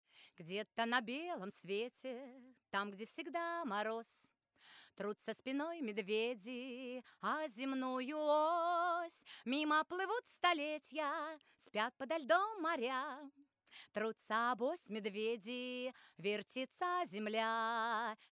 1) Обязательно моно ревер (который "раздувает" создаёт присутствие) 2) Очень короткие до 6-9 мск. стерео делеи которые приближают вокал и делают его объяемным
Вокал ближе.mp3